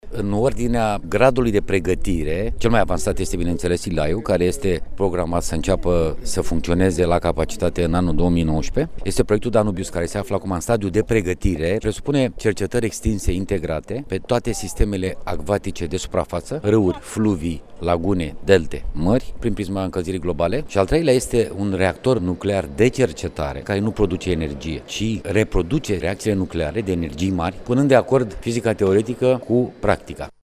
Cercetarea are nevoie de o mai bună finanţare – a apreciat secretarul de stat în Ministerul Cercetării şi Inovării, Lucian Georgescu, ieri, la Institutul Naţional de Fizică şi Inginerie Nucleară de la Măgurele. El a prezentat stadiul proiectelor coordonate de România, printre care se numără şi laserul de mare intensitate.